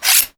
knifesharpener1.wav